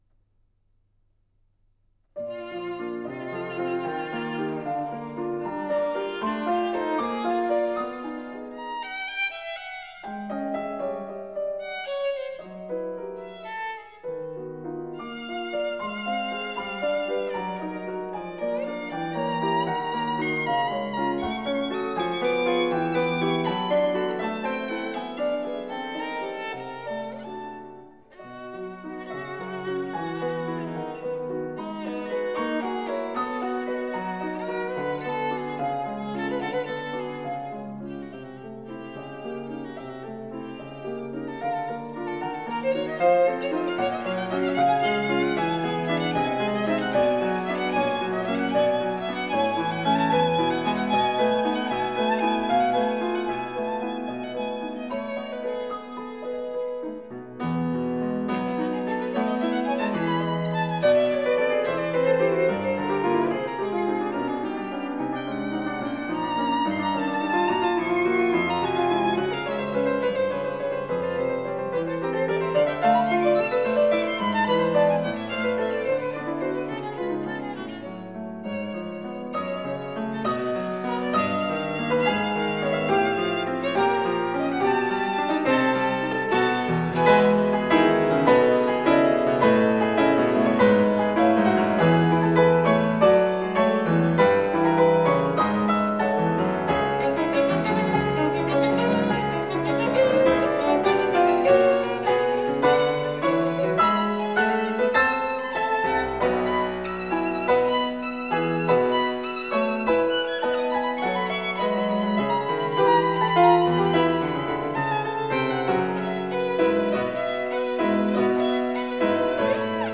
موسیقی کلاسیک به نام " Violinsonate nr. 2, op. 35, III Allegro piacevole " از آهنگساز دانمارکی " Carl Nielsen "